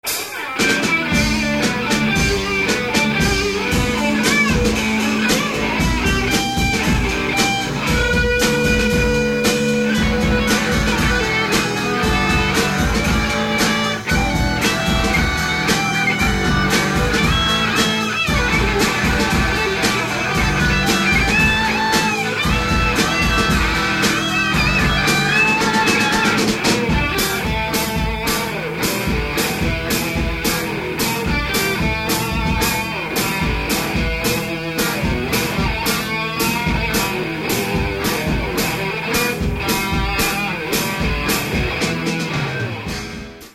Ukazky su nahravana skuska , stero kazetak Tesla Diamant v strede miestnosti, nemali sme spevaka.
solo zo znamej skladby:
Aj ked falosne je to teda dost, ako som to po rokoch pocuval No hanbím sa..